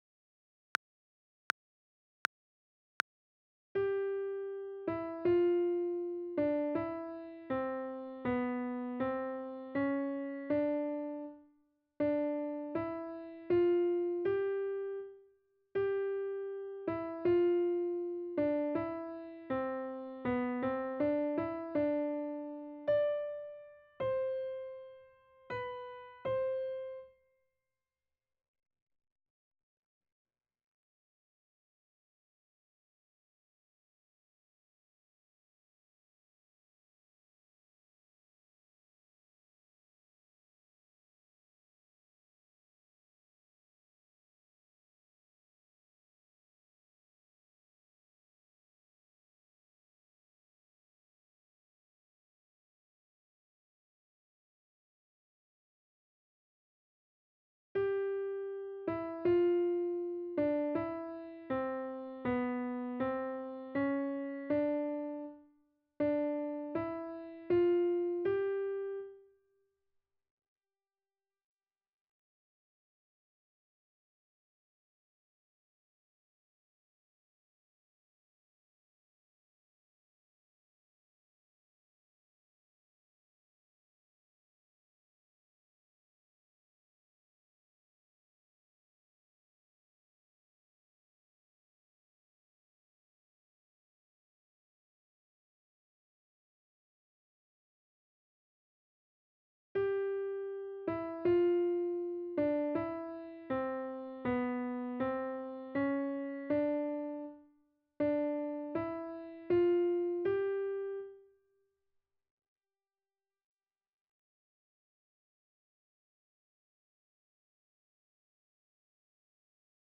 ※課題の中には、無音部分が入っていることもあります。